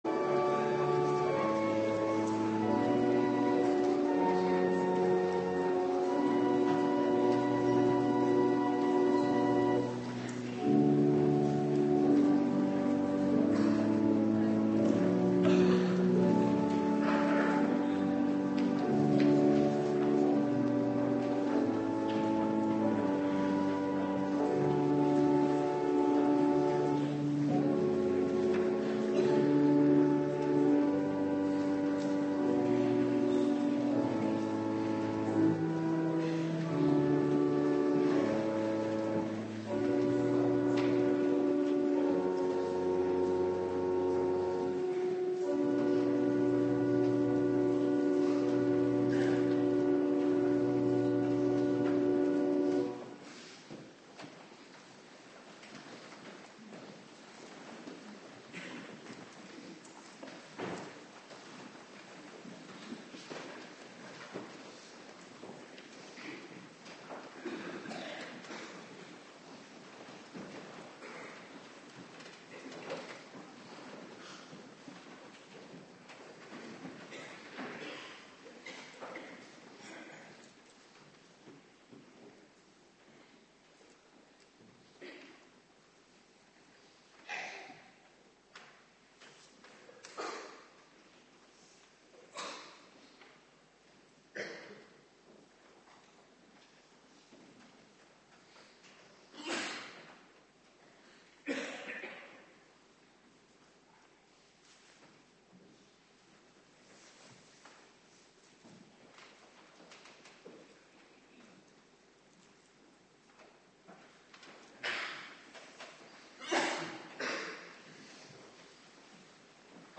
Morgendienst